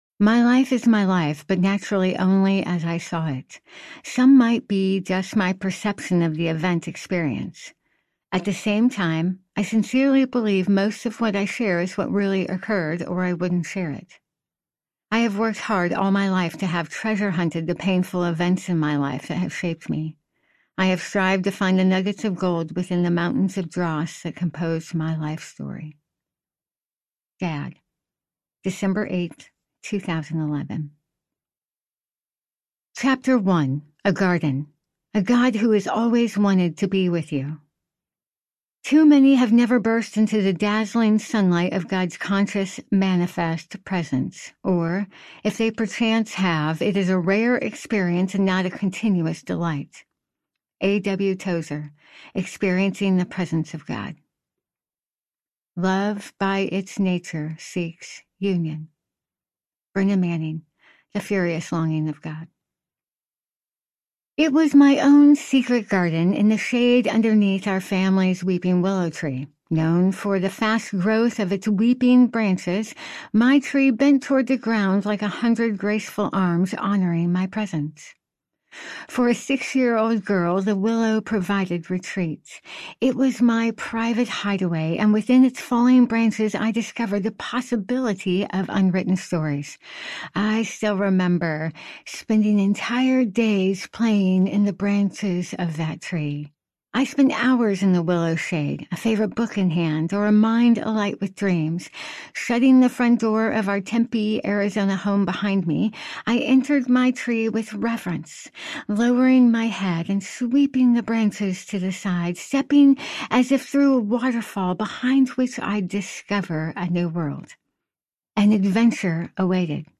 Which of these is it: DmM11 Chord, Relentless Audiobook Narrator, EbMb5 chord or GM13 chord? Relentless Audiobook Narrator